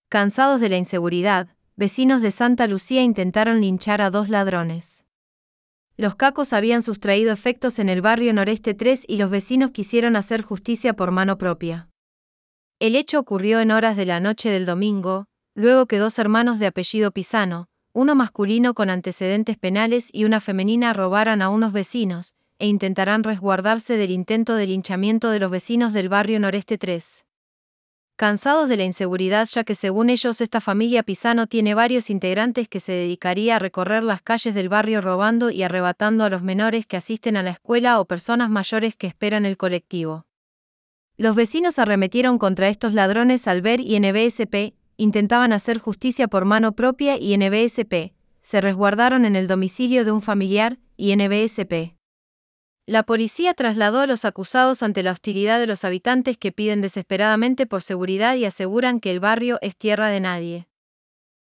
Text_to_Speech-5.wav